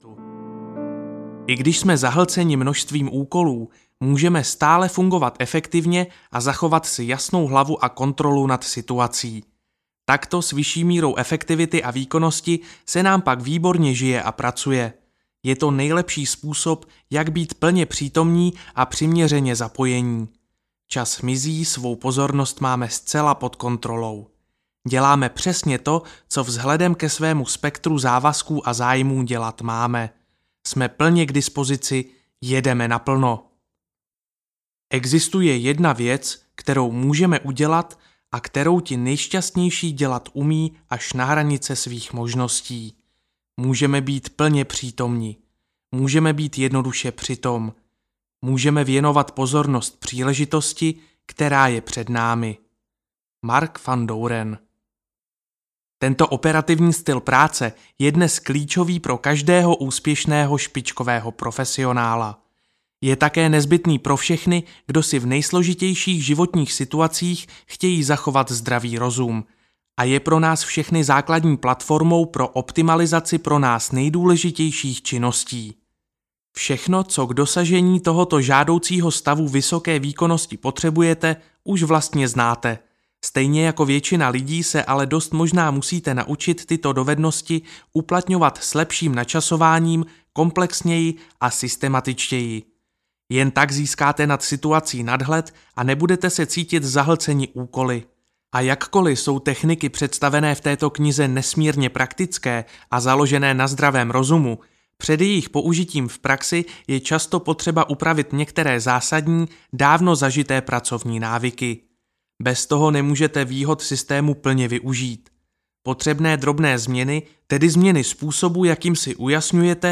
Mít vše hotovo audiokniha
Ukázka z knihy